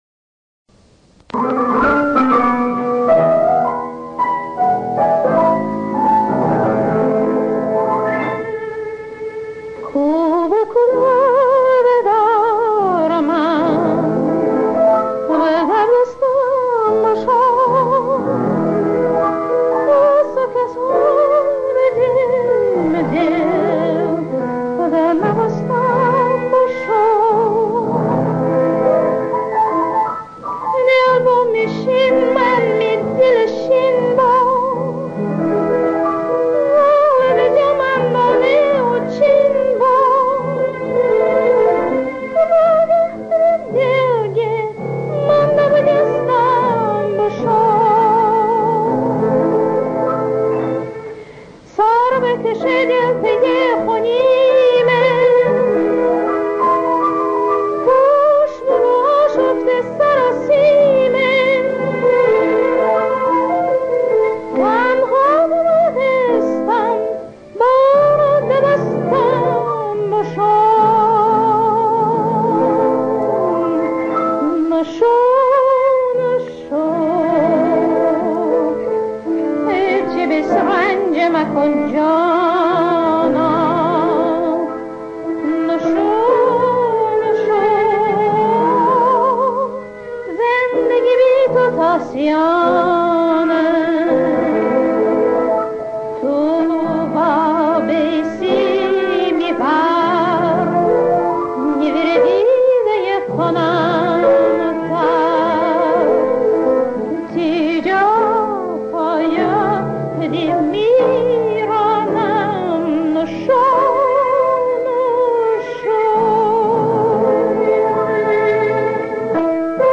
...اپرا...گیلکی....شعر،آواز،موسیقی